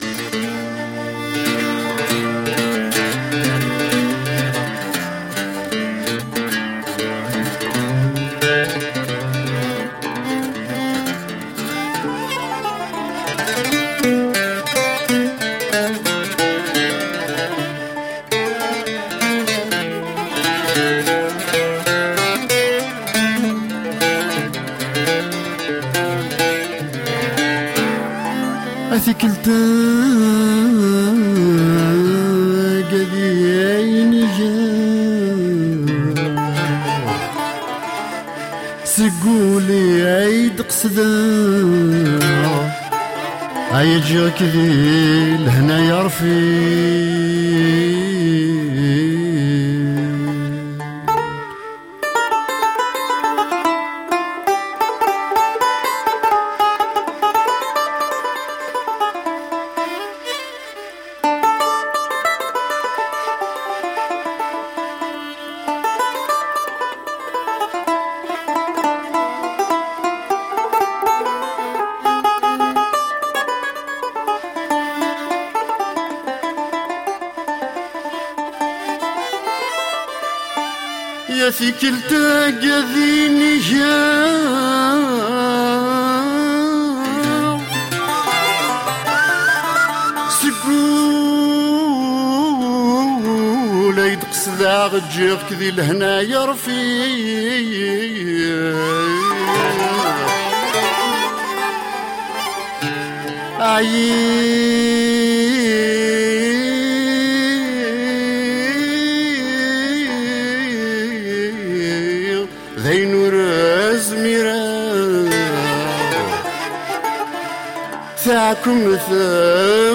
Music of the mountains of kabylia.
Tagged as: World, Folk, Arabic influenced, World Influenced